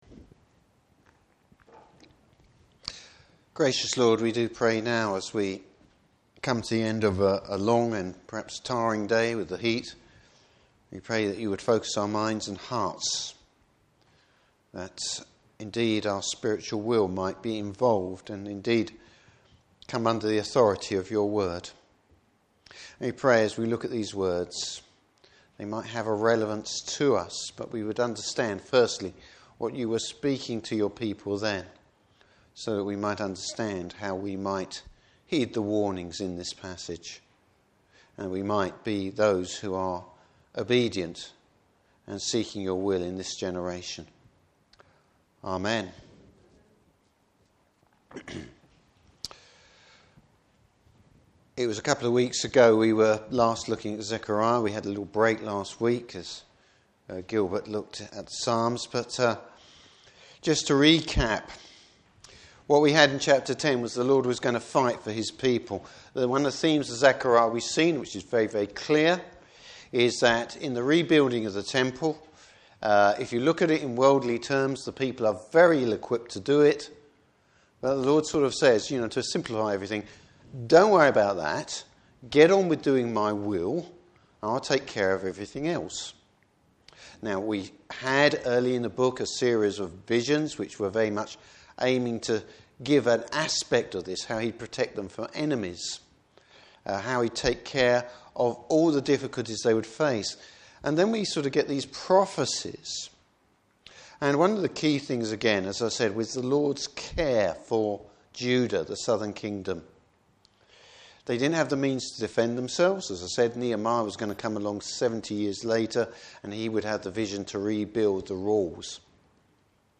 Passage: Zechariah 11:1-3. Service Type: Evening Service Coming judgement!